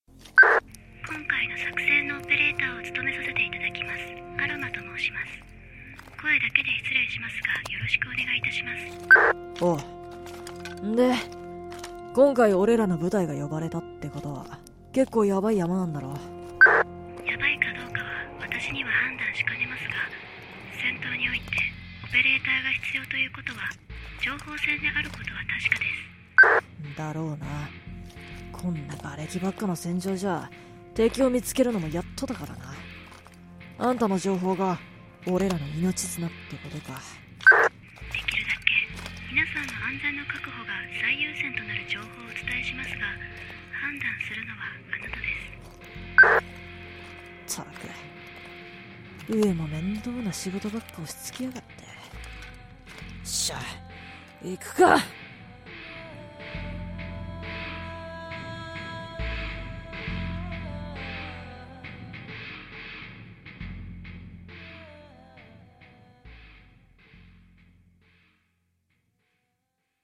オペレーション
青年